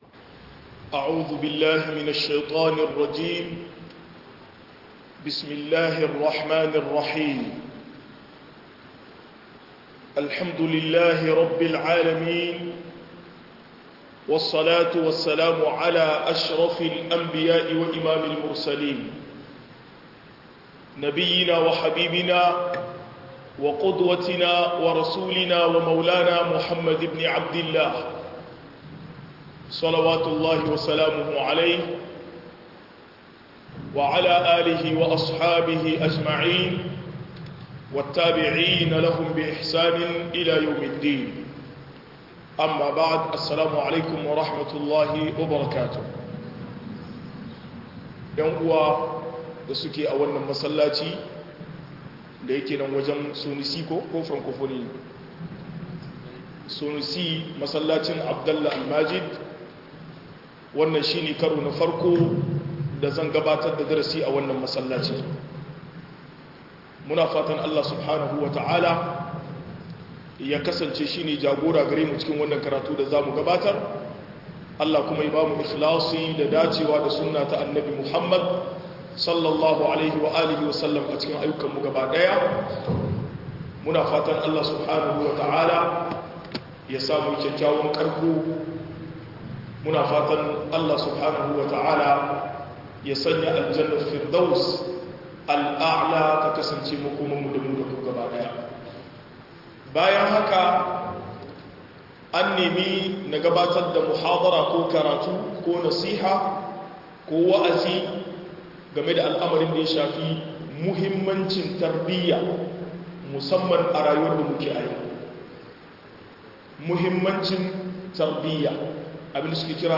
Muhimmancin Tarbiyya - MUHADARA